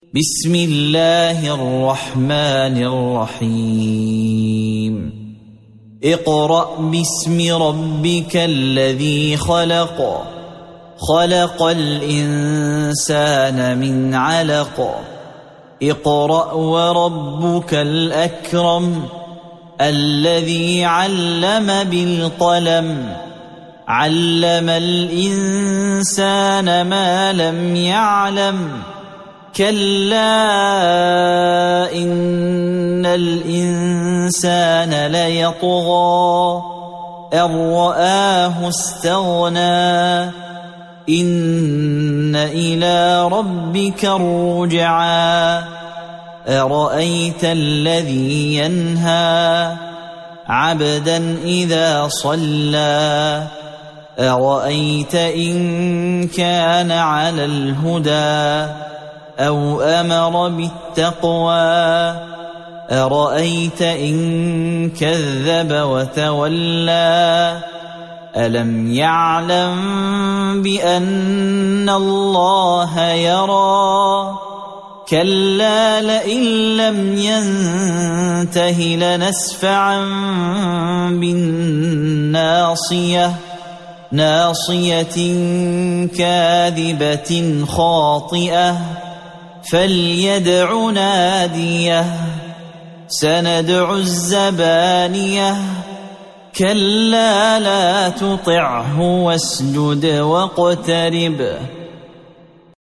سورة العلق مكية عدد الآيات:19 مكتوبة بخط عثماني كبير واضح من المصحف الشريف مع التفسير والتلاوة بصوت مشاهير القراء من موقع القرآن الكريم إسلام أون لاين